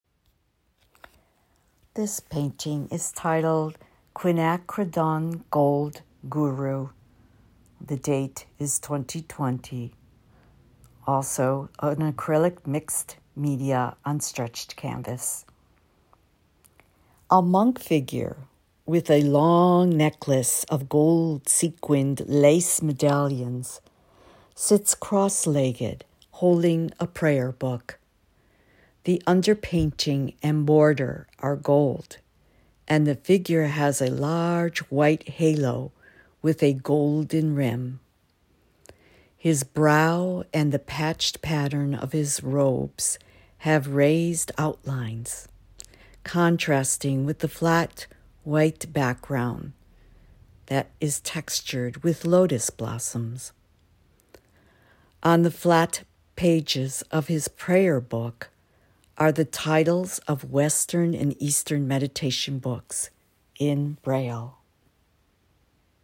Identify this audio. Audio description: